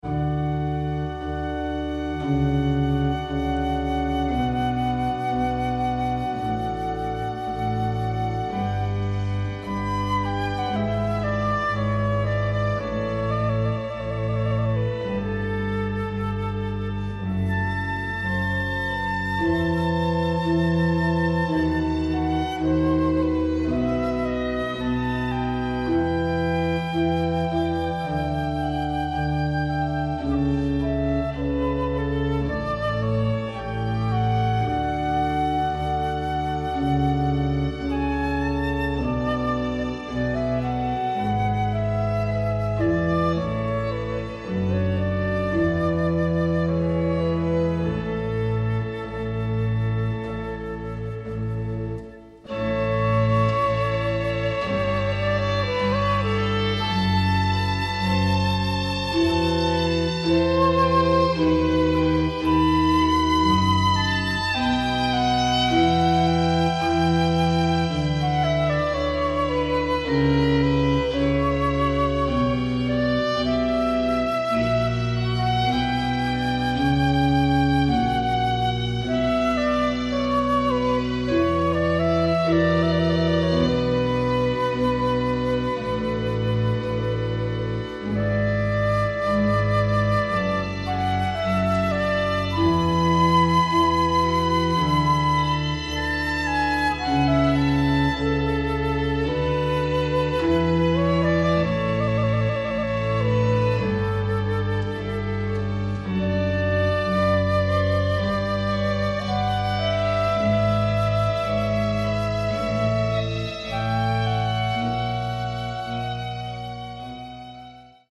Orgel
Trompete
Querflöte, Sopransax
Violine